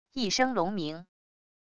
一声龙鸣wav音频